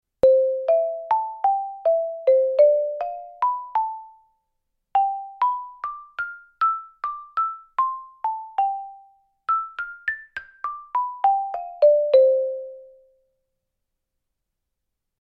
Tonumfang c2 - a3 mit 16 Stäbe 32 x 14 mm aus GRILLODUR
Resonanzkasten aus 12 mm starken Kiefernholz
Klangbeispiel mit einem Schlägel...